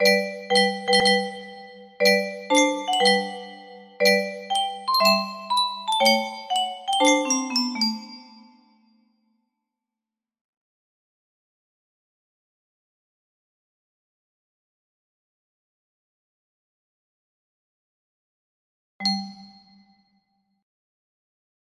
lois 2 music box melody